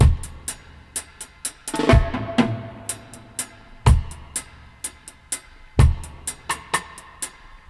62-FX-03.wav